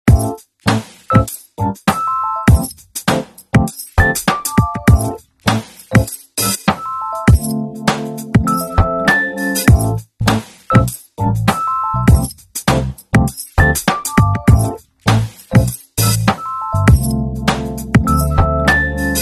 This is Martin s218+ subwoofer, sound effects free download